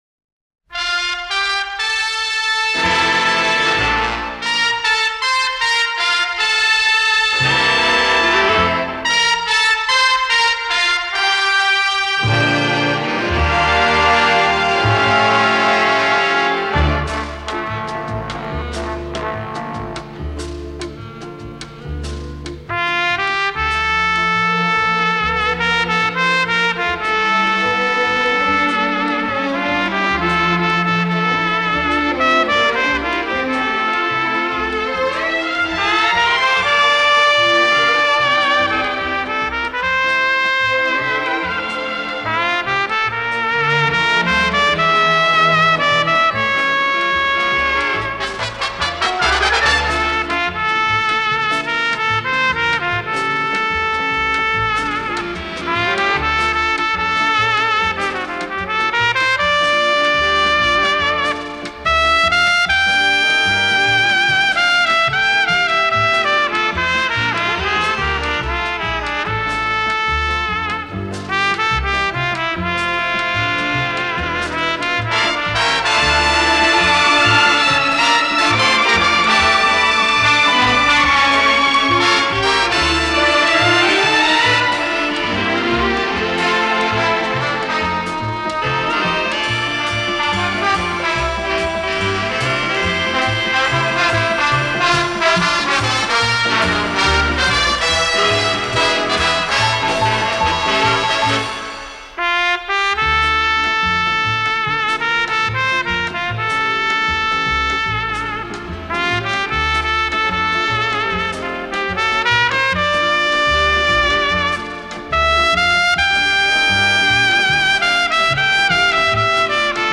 Ре минор
труба.
Звучит отменно, а ведь уже почти полвека!.